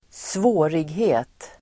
Uttal: [²sv'å:righe:t]